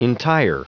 Prononciation du mot entire en anglais (fichier audio)
Prononciation du mot : entire